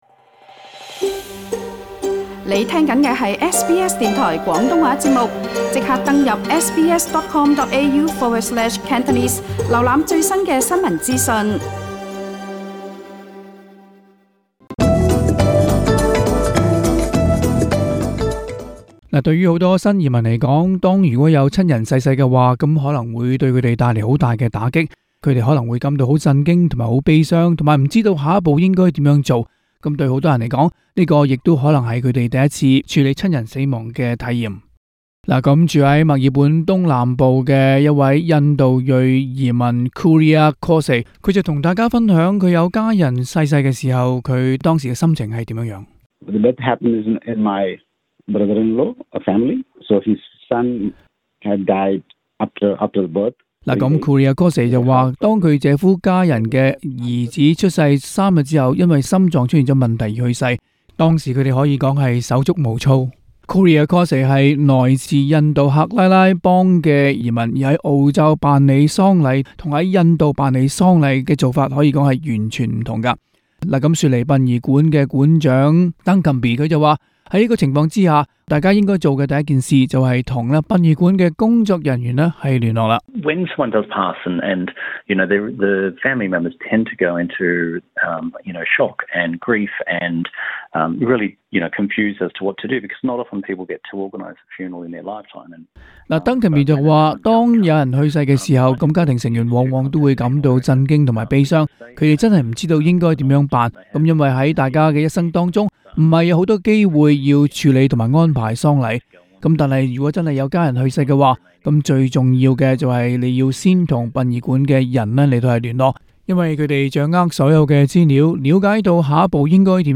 Source: Getty Images SBS廣東話節目 View Podcast Series Follow and Subscribe Apple Podcasts YouTube Spotify Download (9.03MB) Download the SBS Audio app Available on iOS and Android 移民之旅往往會遇上一些預料之外或不能確定的人生經歷。